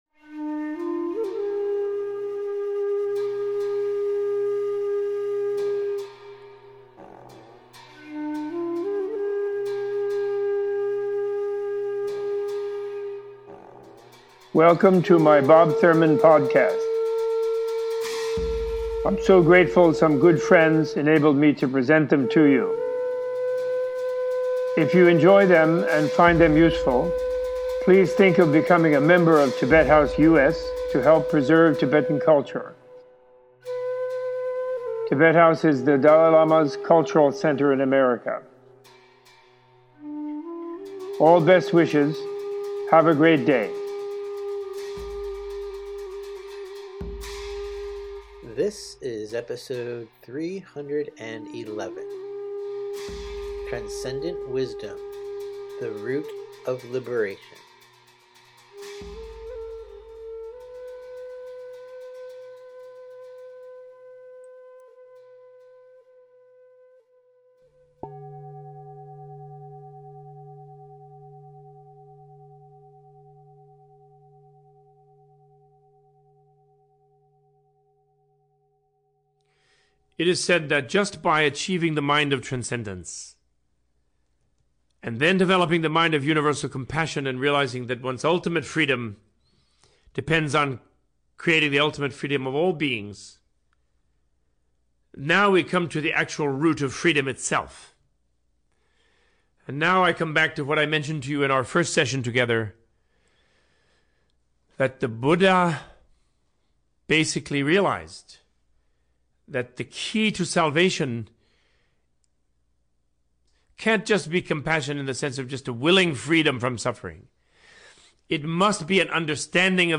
In this episode Robert Thurman details the concept of emptiness, giving a teaching on the power of the mind to develop transcendent wisdom in order to understand reality as it is using critical thinking, debate, direct experience and meditation. This podcast includes discussions of: the scientific nature of the Buddha’s teachings, the illusion-like nature of the appearance of reality, the three types of wisdom (wisdom born of learning, critical reflection, and meditative insight), and an explanation of the differences between Objective Selflessness and Subjective Selflessness. Thurman concludes this episode with a guided Four Keys of Selflessness meditation weaving in the modern understanding of quantum physics and the scientific method.